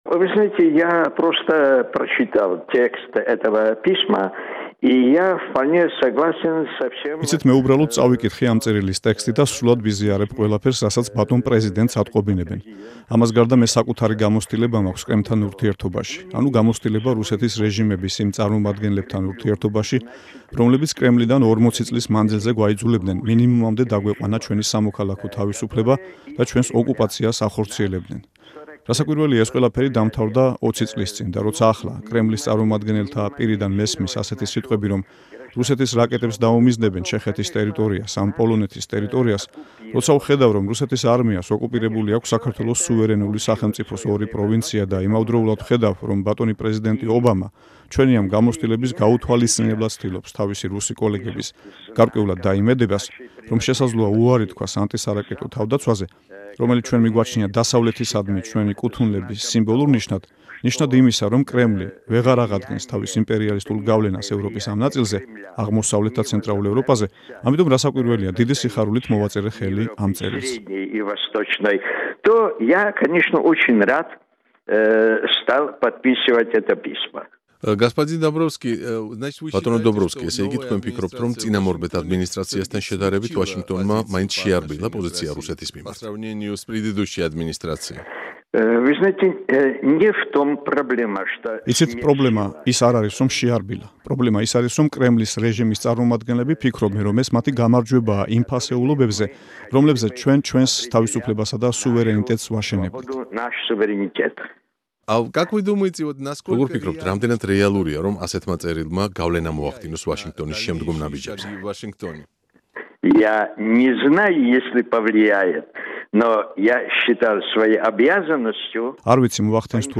ინტერვიუ ლუბოშ დობროვსკისთან